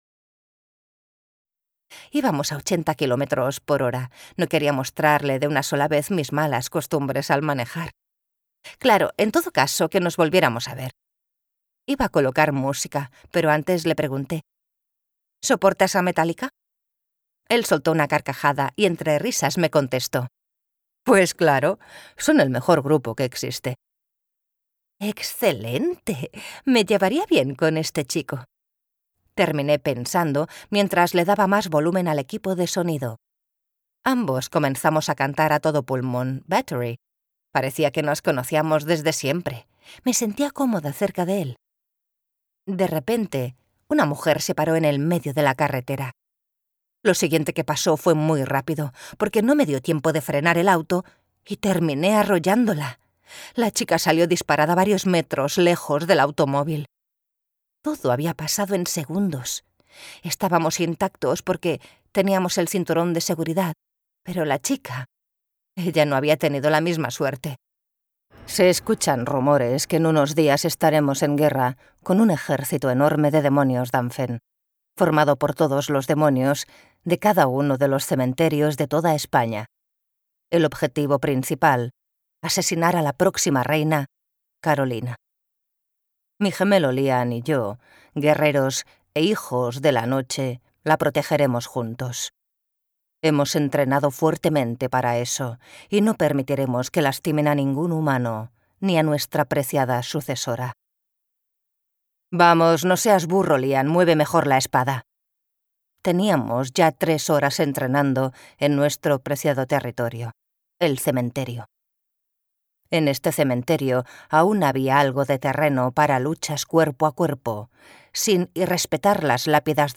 Audiolibro El Reino (The Kingdom)